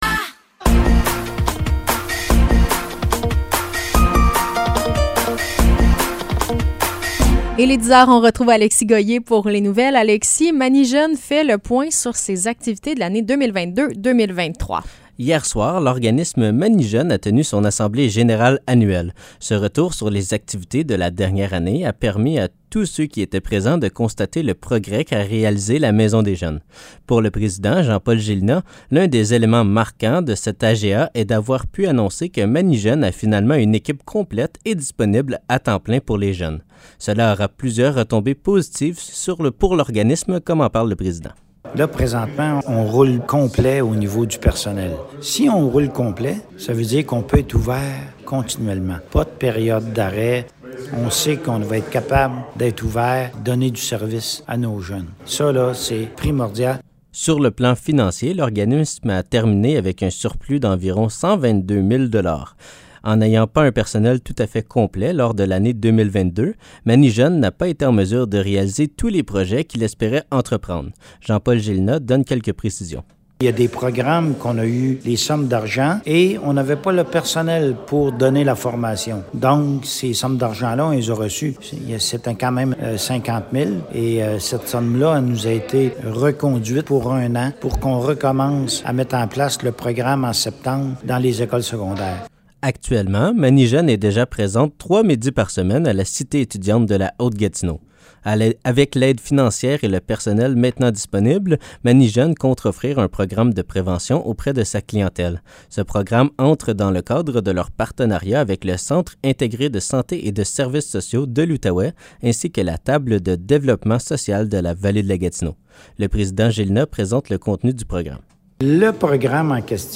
Nouvelles locales - 21 juin 2023 - 10 h